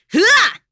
Nicky-Vox_Attack4.wav